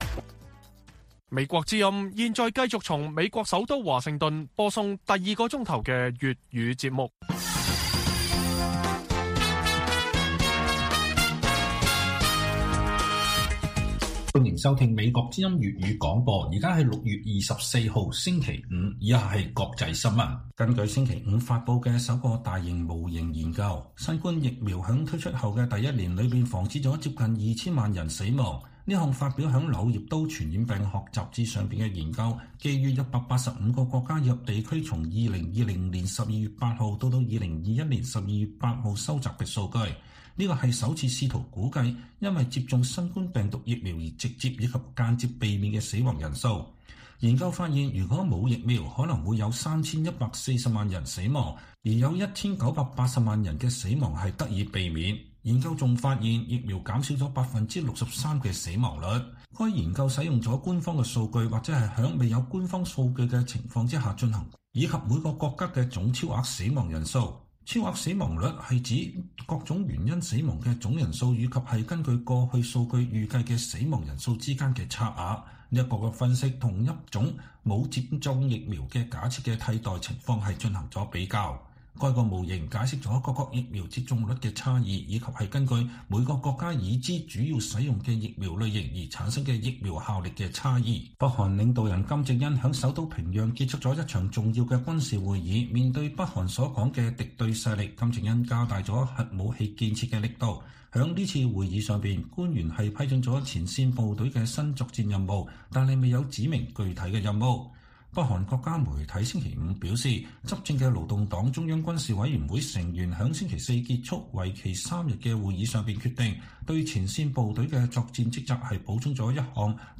粵語新聞 晚上10-11點: 《柳葉刀》研究指新冠病毒疫苗問世第一年拯救2000萬人生命